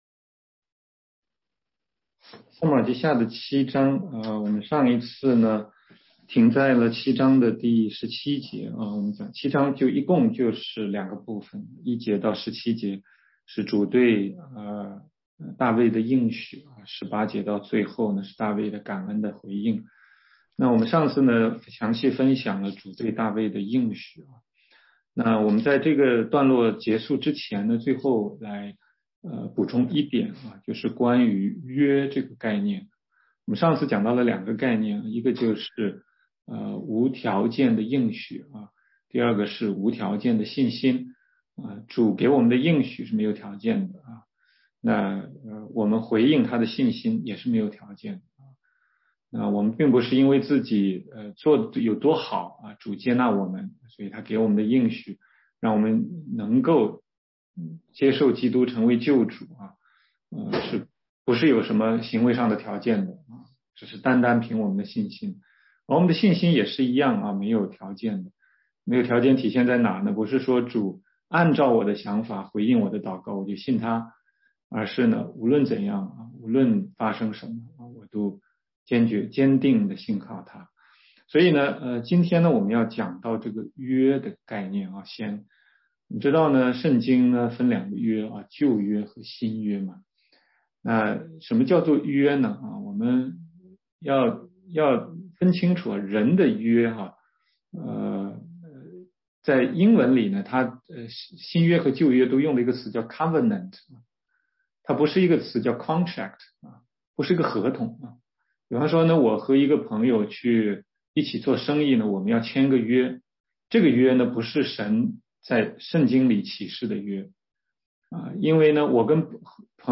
16街讲道录音 - 撒母耳记下7章18-29节：大卫对神的应许的祷告回应
全中文查经